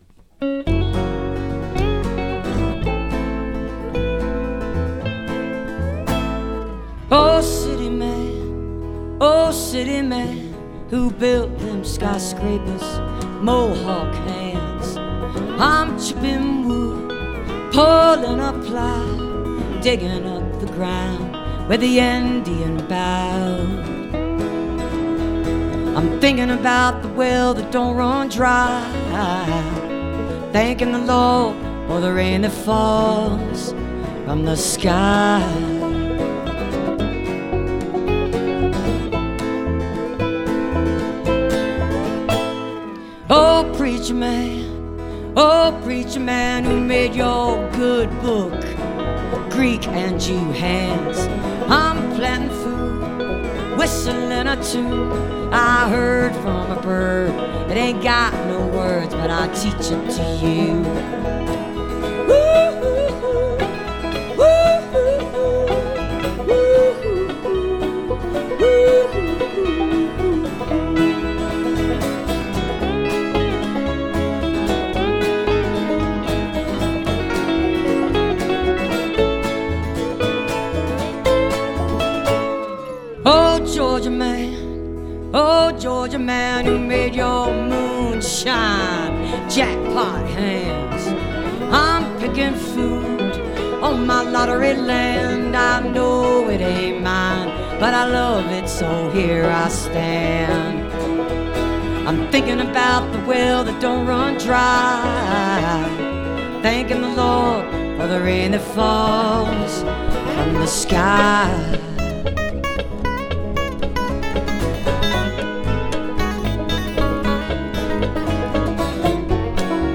(captured from a websteam)